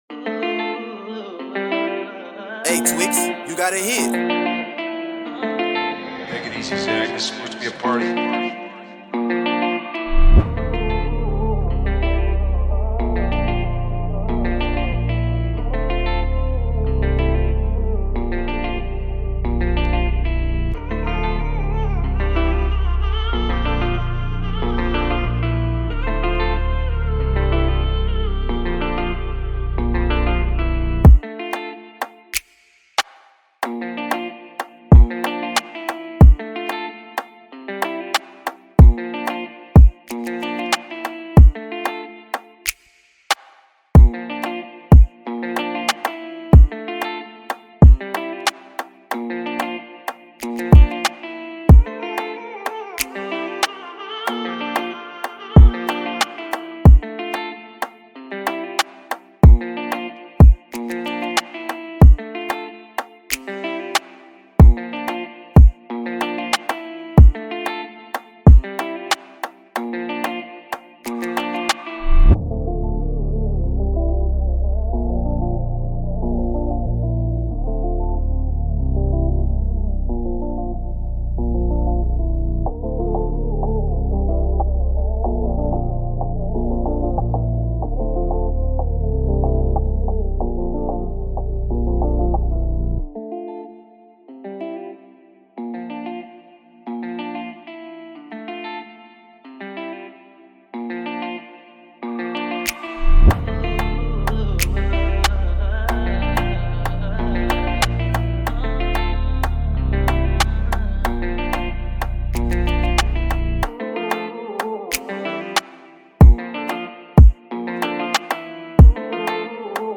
Hip-Hop Instrumental